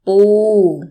– bpuu